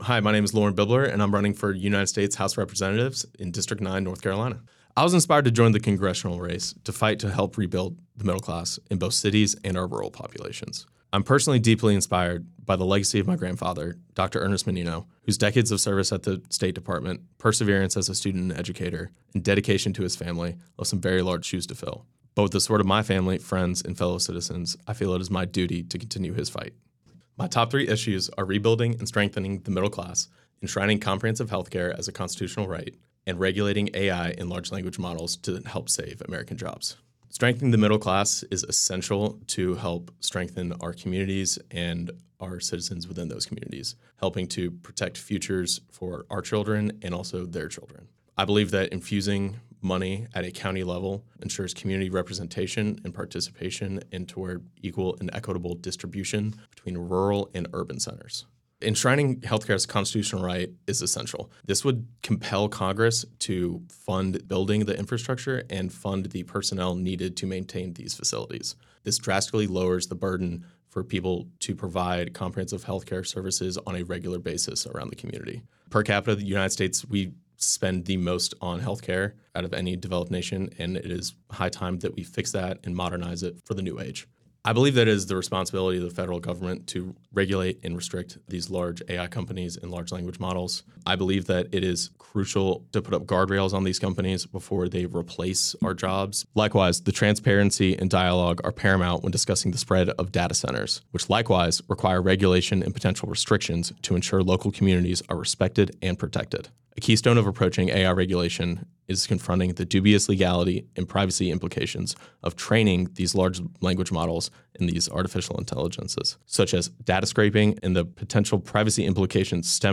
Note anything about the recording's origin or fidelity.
97.9 The Hill spoke with each primary candidate, asking these questions that are reflected in the recorded responses: